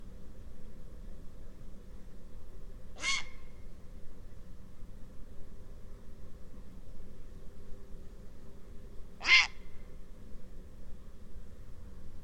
Garza real
Ardea cinerea
Canto
Garza-real.mp3